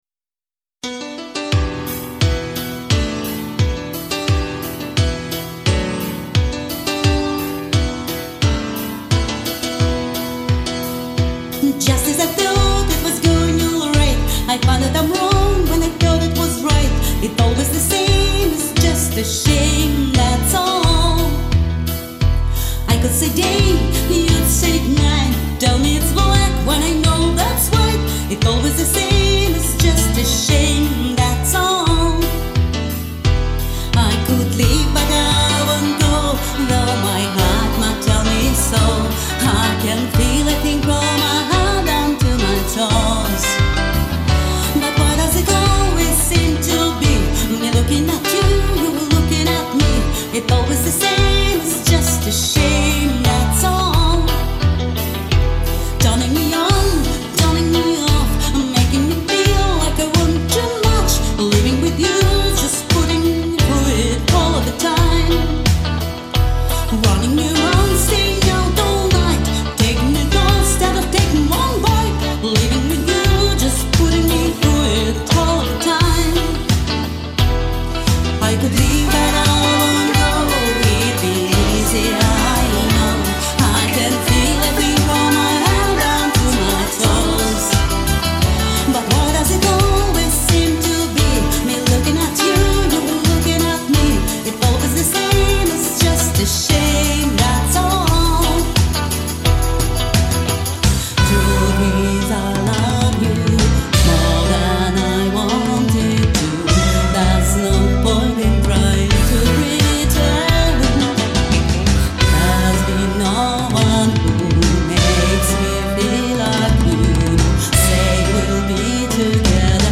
С таким драйвом!